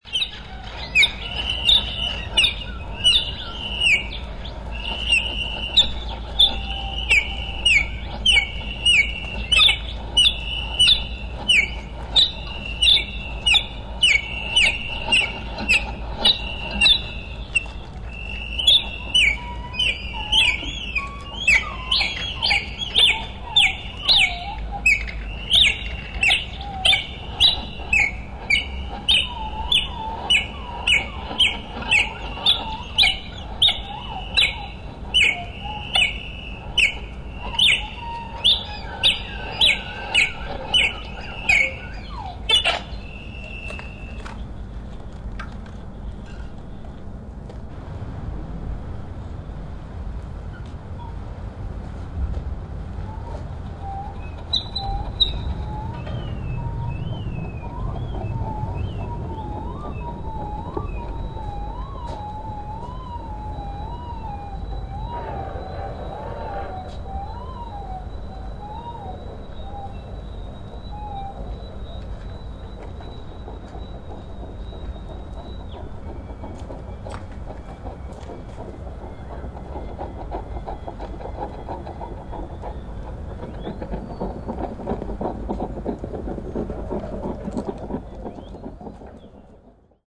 No Audience Side